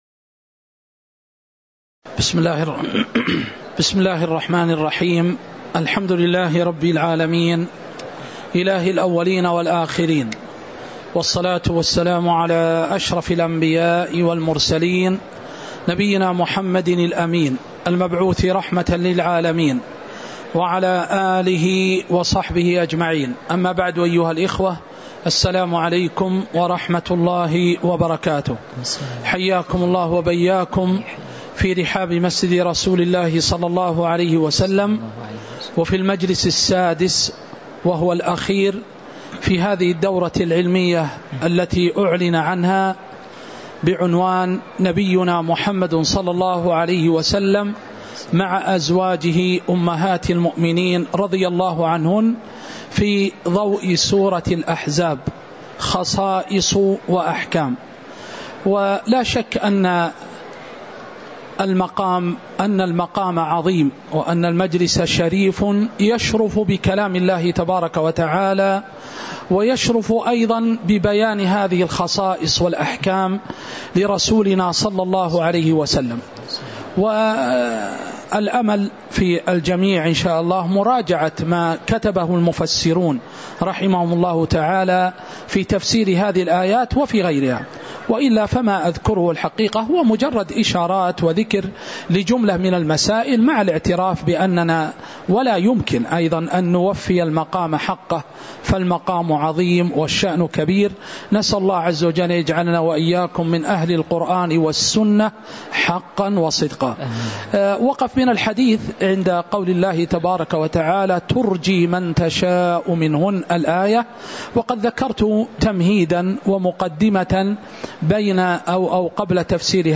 تاريخ النشر ٢٩ ربيع الثاني ١٤٤٥ هـ المكان: المسجد النبوي الشيخ